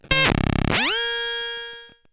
不思議系効果音です。
ビユーワーン・・・